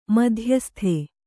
♪ madhyasthe